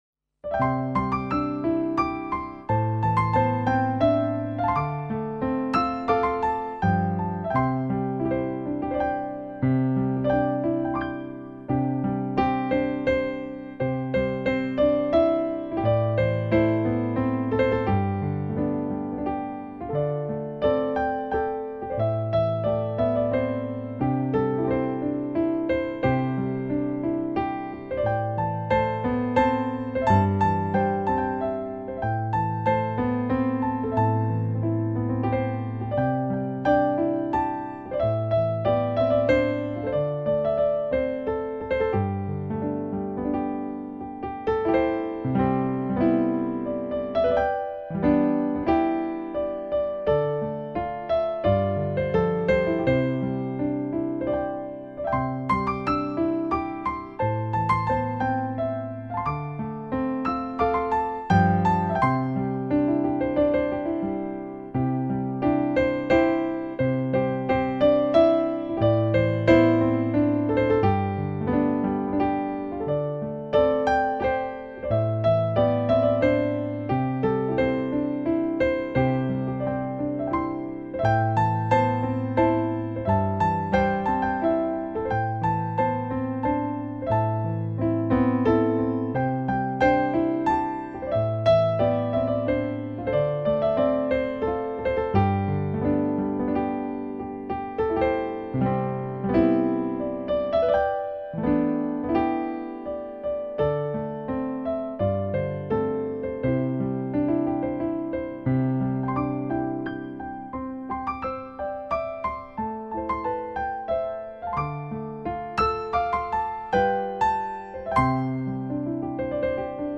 类型: 轻音乐
都听到眉飞色舞，此碟钢琴音色有极高的像真度，琴声由小到大的幅度仍甚惊人，在部分比较急越的段
落中，琴声动态之大令人高呼过瘾。